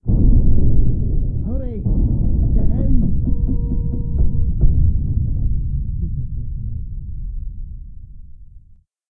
scpcb-godot/SFX/Room/Intro/Commotion/Commotion2.ogg at 81ef11964c8ee75b67faf28b3237c393a288471c
Commotion2.ogg